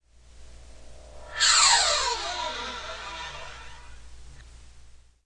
描述：在低音吉他弦上上下运行硬币（俯仰/反向操纵）
Tag: 音调 低音 操纵 MTC500-M002-S14 吉他